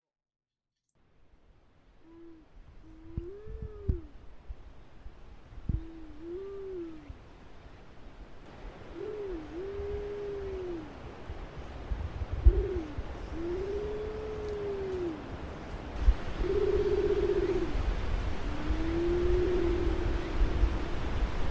• ほか動物の声（屋久島にて収録）
カラスバト